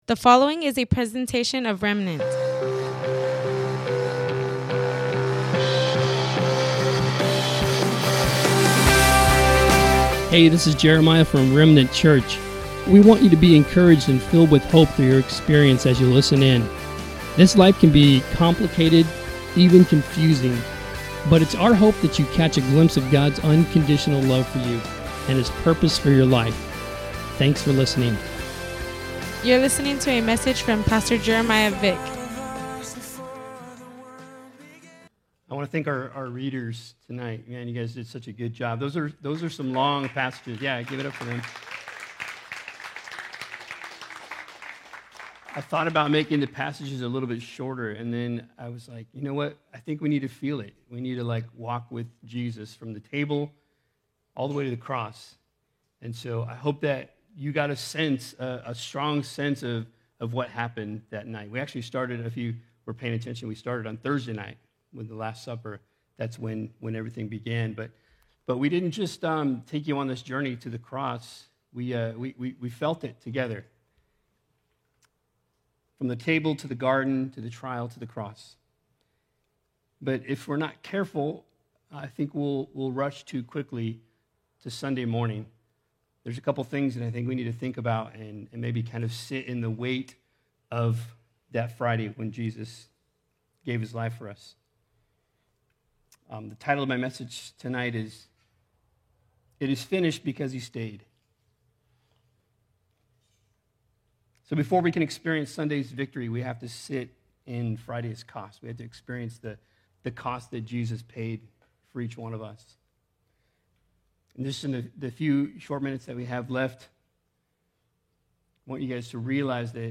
It Is Finished Because He Stayed – Sermon Only
Welcome to the livestream of our Good Friday Service at Remnant Church in Imperial Valley, CA.
ItIsFinishedSermon.mp3